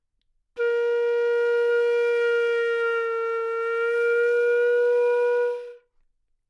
长笛单音（吹得不好） " 长笛 Asharp4 badpitch
Tag: 好声音 单注 多样本 Asharp4 纽曼-U87 长笛